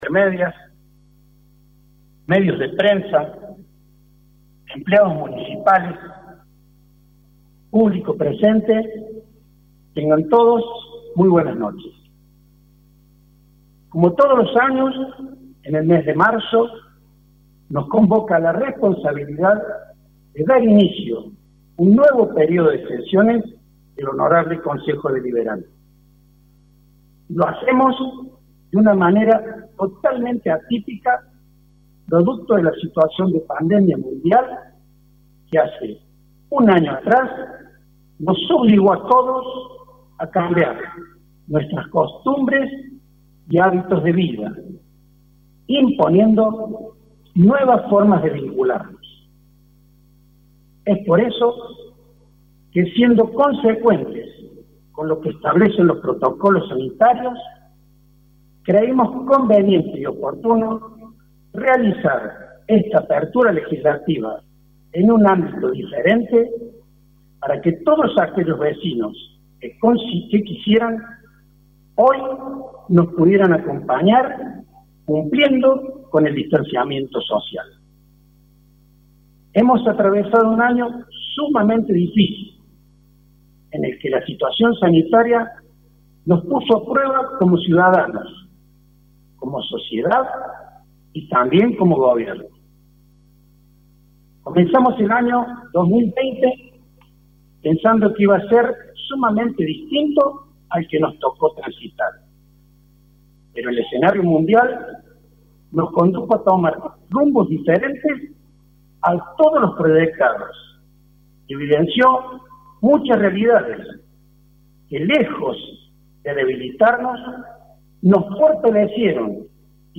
CLAUDIO CHAVERO EN LA APERTURA DE SESIONES DEL CONCEJO DELIBERANTE.
Apertura-del-consejo-deliverante.mp3